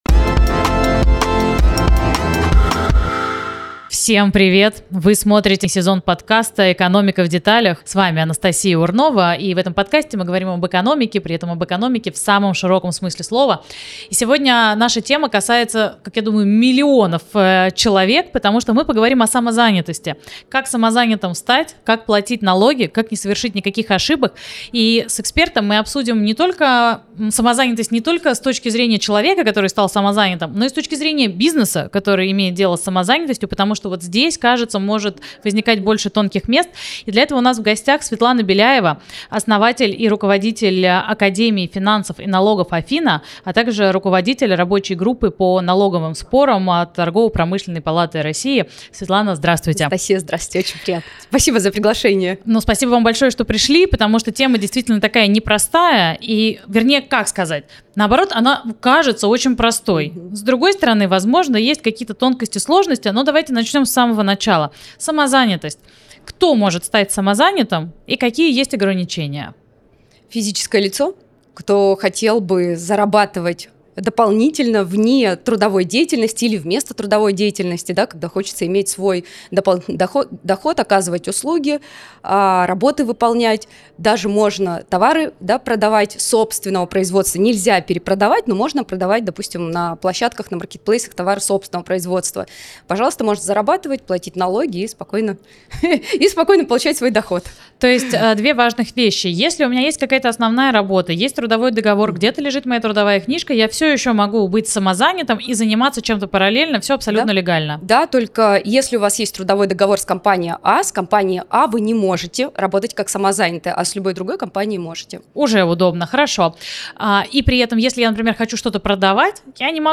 Эксперт рассказывает, как зарегистрироваться самозанятым всего за минуту с помощью приложения «Мой налог», при каких условиях аннулированные чеки могут привести к штрафам и пеням и как выстроить работу с заказчиком, чтобы не вызывать вопросов у налоговой. Подкаст «Экономика в деталях» — цикл бесед об устройстве городской экономики и о грамотном подходе к жизни и самореализации в мегаполисе.